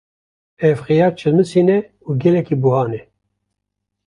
Pronunciado como (IPA)
/xɪˈjɑːɾ/